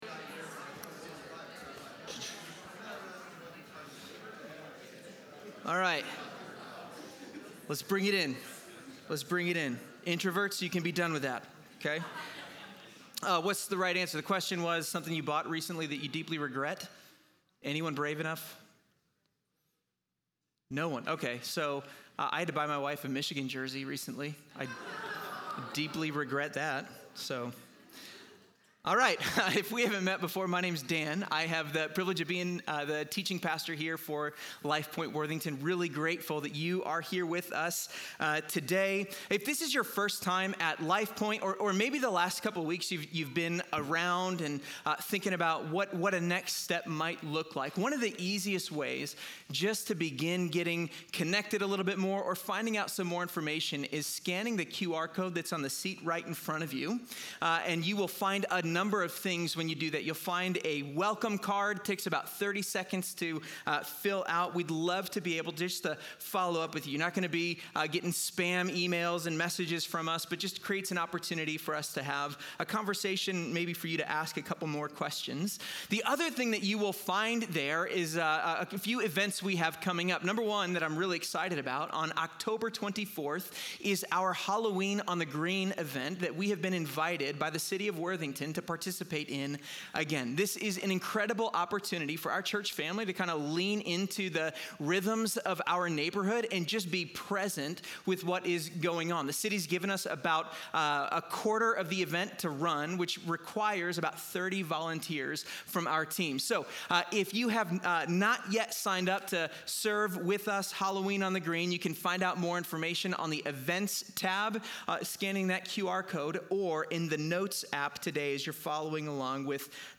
In this sermon on James 2:1–9, the speaker confronts the issue of favoritism within the church, arguing that transactional relationships—measuring people by status or usefulness—undermine the gospel. He traces how the world’s values seep into Christian community and warns that partiality corrupts our witness.